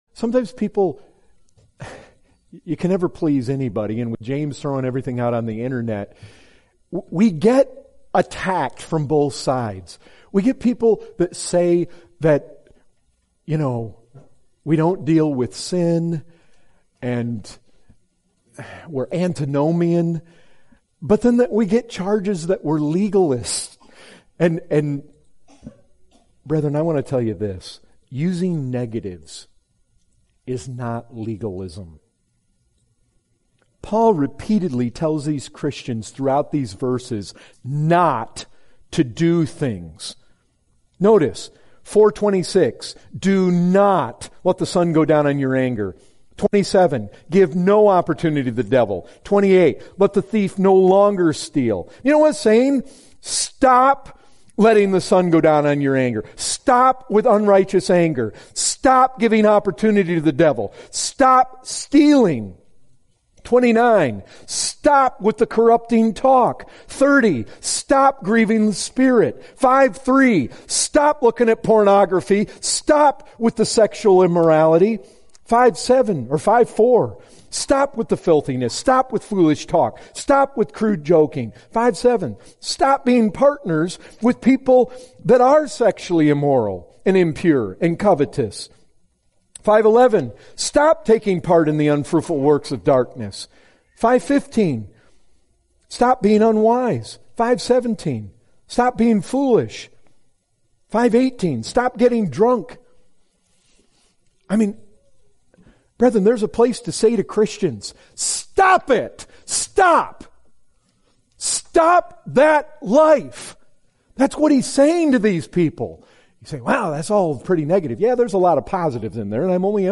2019 Category: Excerpts Topic